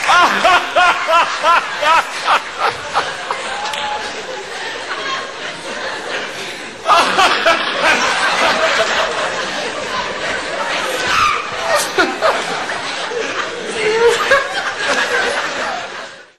Risada do Bira
Risada do Bira do sexteto do Programa do Jô Soares.
risada-bira-jo-soares.mp3